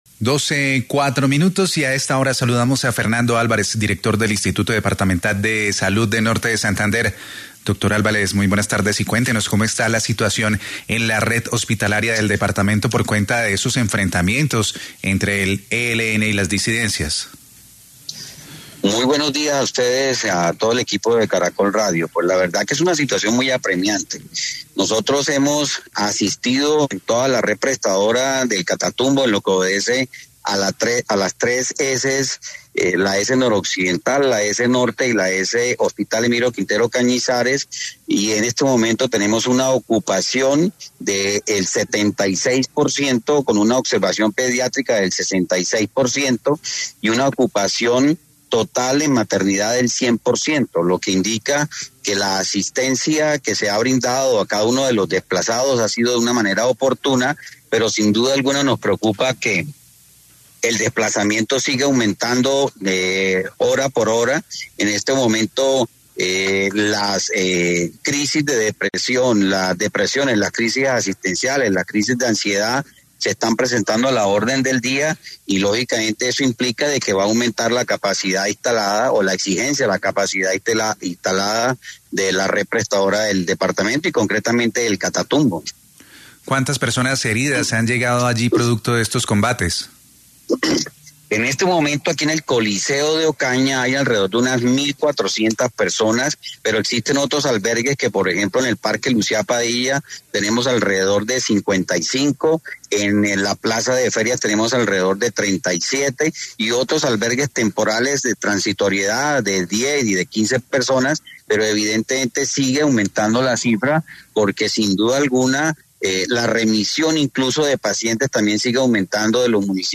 Fernando Álvarez, director del Instituto Departamental de Salud del Norte de Santander, explicó en entrevista con Caracol Radio la compleja situación que enfrentan los servicios de salud.